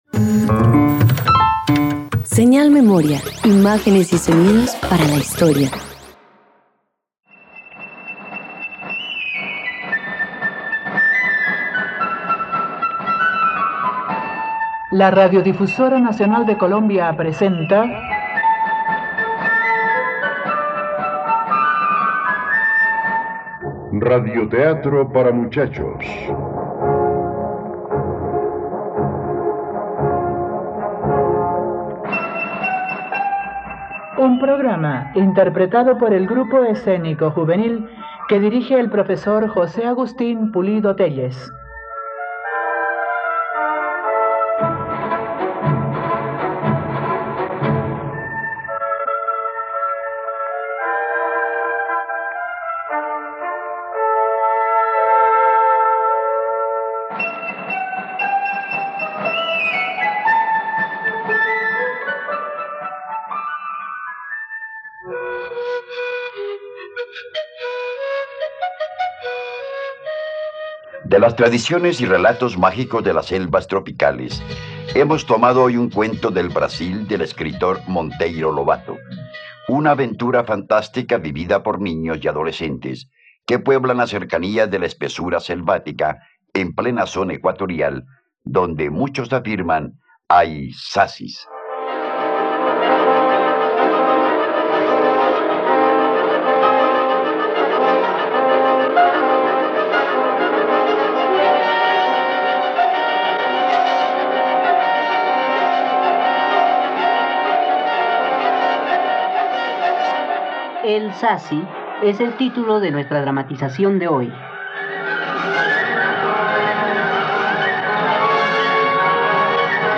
El sací - Radioteatro dominical | RTVCPlay